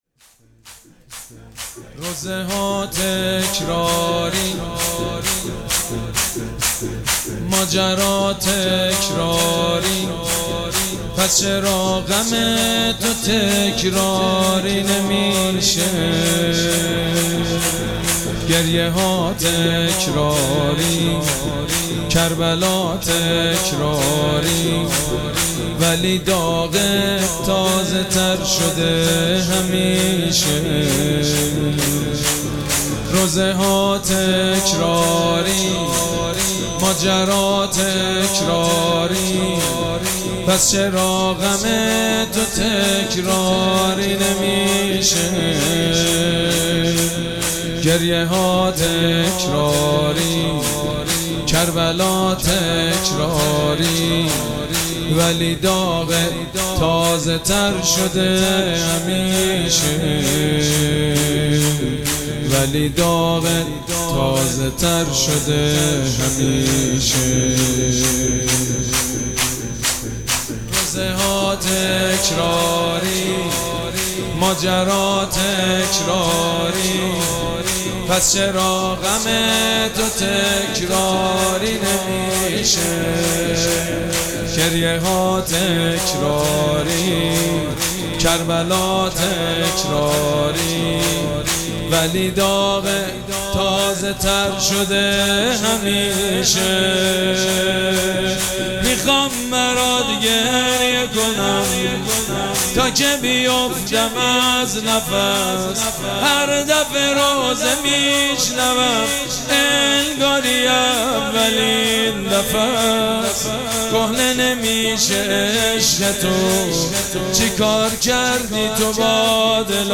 مراسم عزاداری شب هفتم محرم الحرام ۱۴۴۷
شور
مداح
حاج سید مجید بنی فاطمه